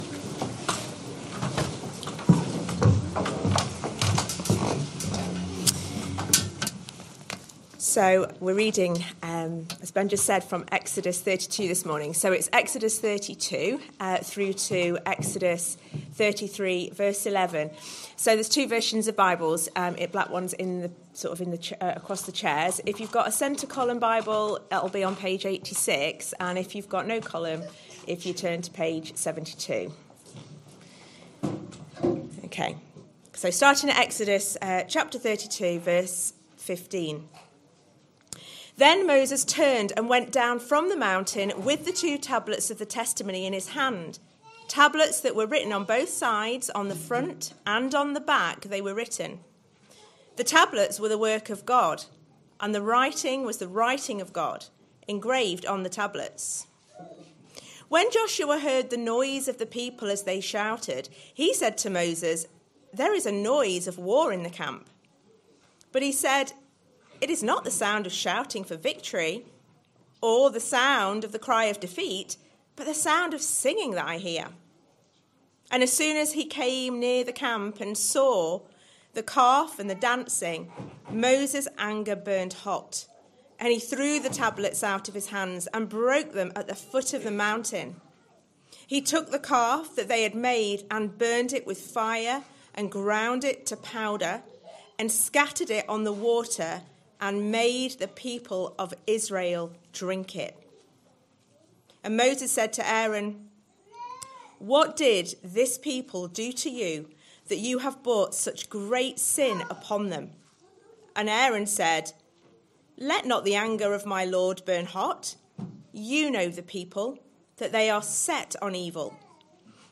Christ Church Sermon Archive
Sunday AM Service Sunday 1st February 2026 Speaker